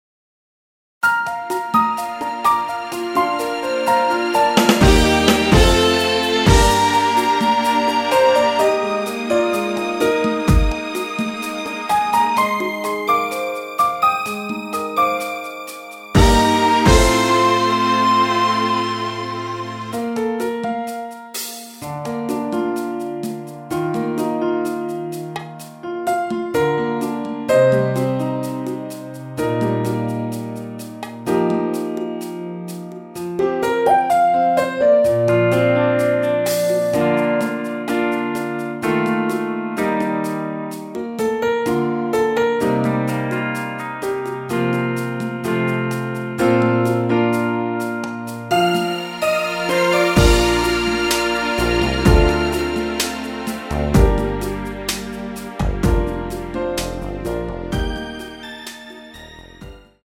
원키에서(+1)올린 MR입니다.
Bb
◈ 곡명 옆 (-1)은 반음 내림, (+1)은 반음 올림 입니다.
앞부분30초, 뒷부분30초씩 편집해서 올려 드리고 있습니다.
축가 MR